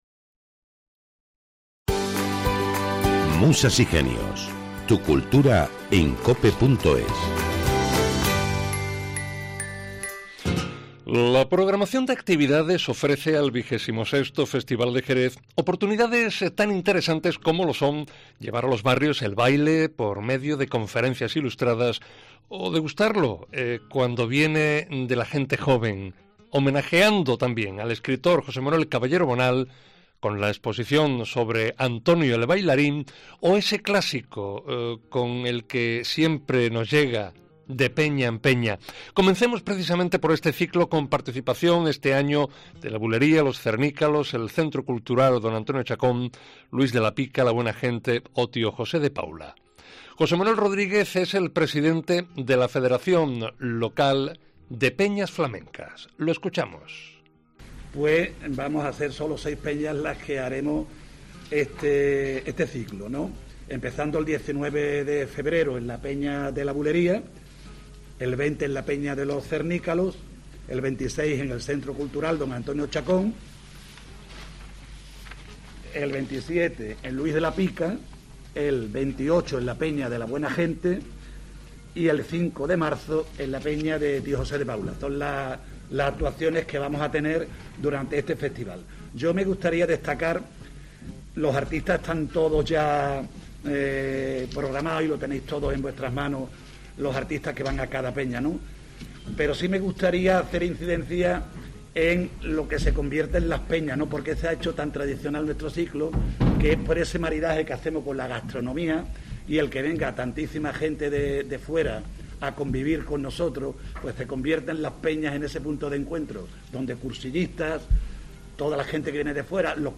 Escucha aquí voces que contribuyen a conocer estas iniciativas en un acto presidido por el delegado de Dinamización Cultural del Ayuntamiento de Jerez Francisco Camas.